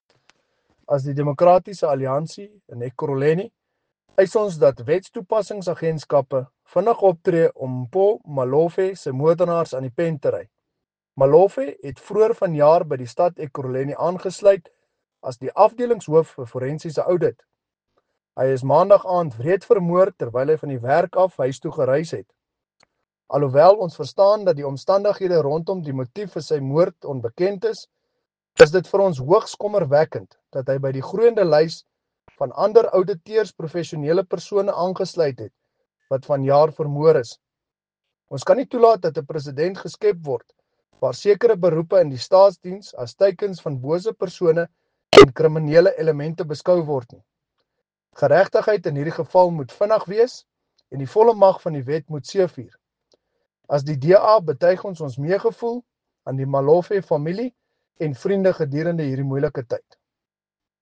Note to Editors: Please find English and Afrikaans soundbites by Cllr Brandon Pretorius